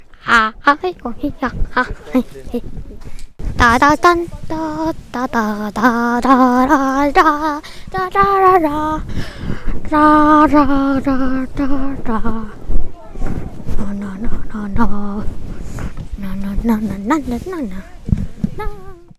흥얼거림.mp3